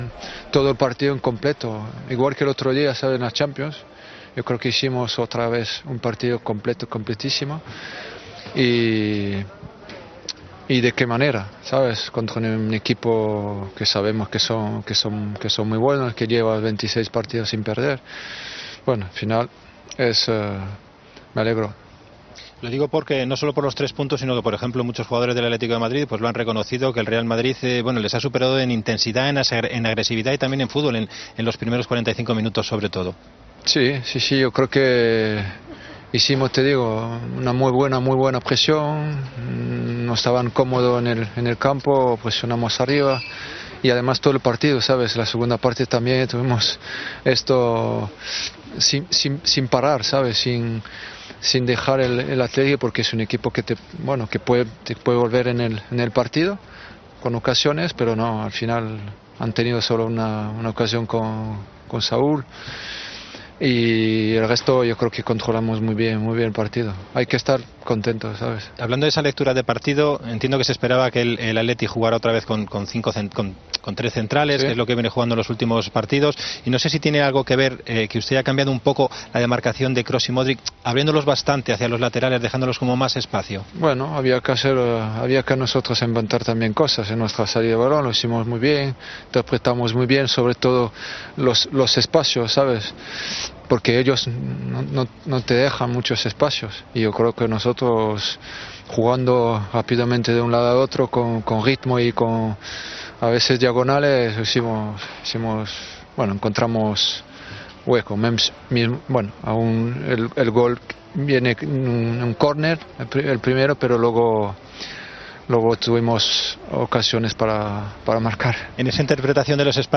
Tras la victoria en el derbi